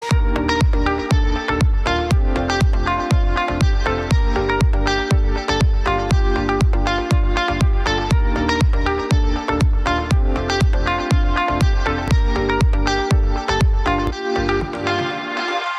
• Качество: 320, Stereo
гитара
deep house
спокойные
без слов
инструментальные
Жанр: Deep House